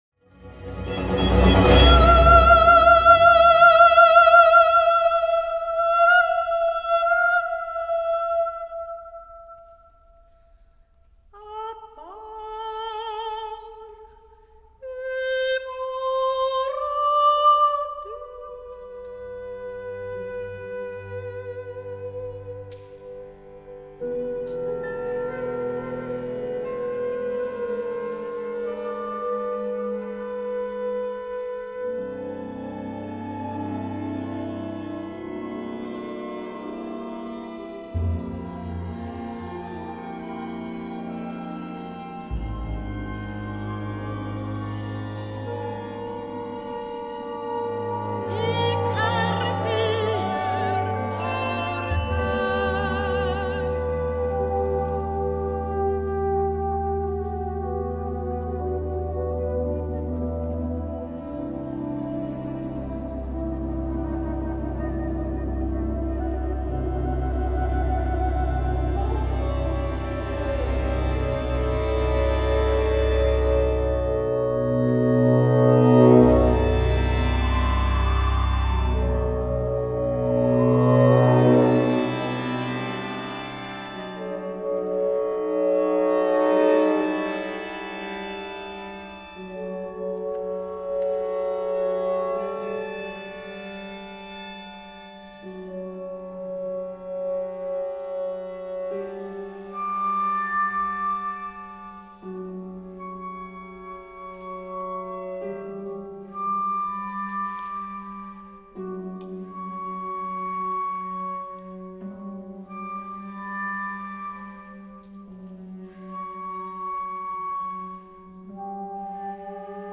für Sopran und kleines Orchester (2009)